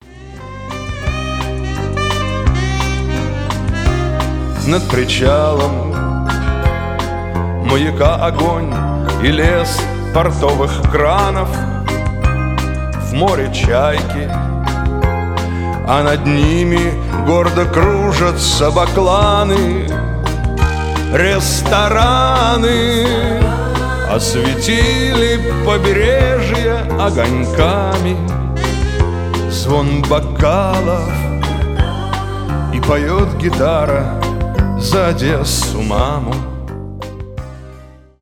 душевные , шансон , саксофон